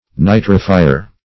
\Ni"tri*fi`er\